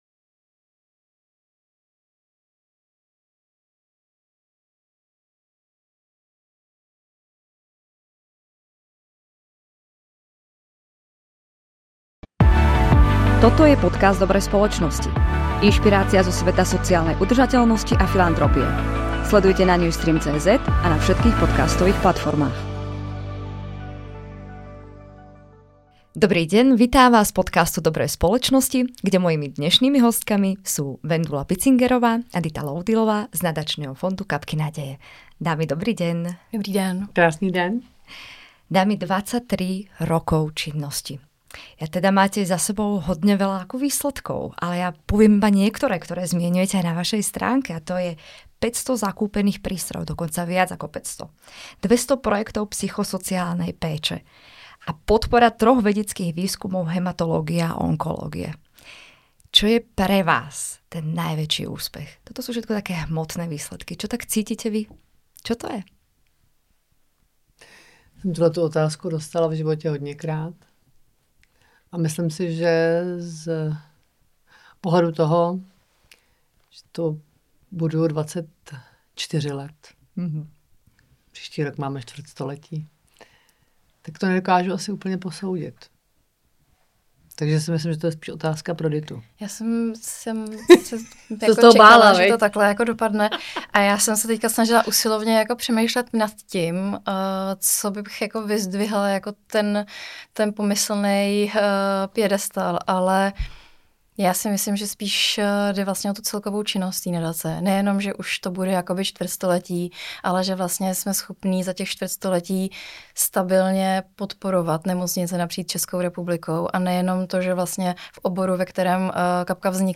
podcastové série rozhovorů, které představují hlavní tváře konference Good Company Circle (GCC)